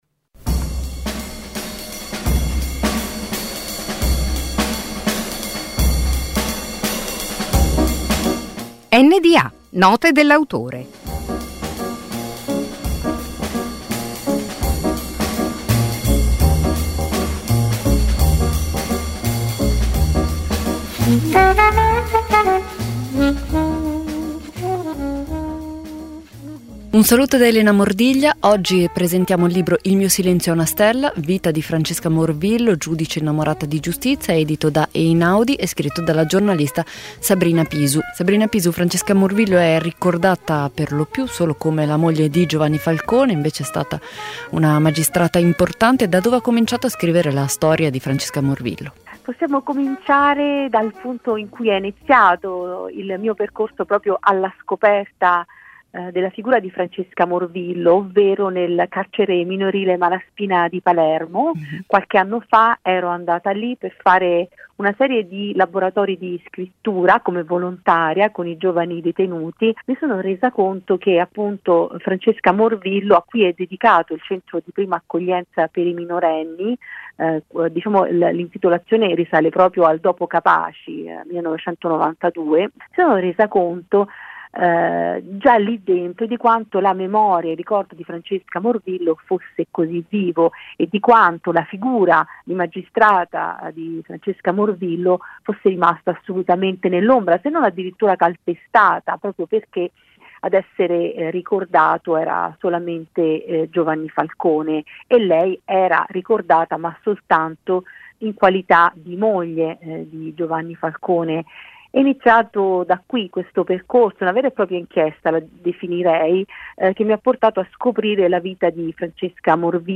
Un appuntamento quasi quotidiano, sintetico e significativo con un autore, al microfono delle voci di Radio Popolare. Note dell’autore è letteratura, saggistica, poesia, drammaturgia e molto altro.